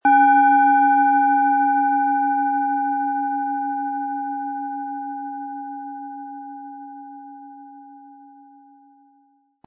Planetenschale® Gehirnhälften ausgleichen & Reden und Ausdrücken können mit Merkur, Ø 14,1 cm, 400-500 Gramm inkl. Klöppel
Planetenton 1
Um den Originalton der Schale anzuhören, gehen Sie bitte zu unserer Klangaufnahme unter dem Produktbild.
SchalenformBihar
MaterialBronze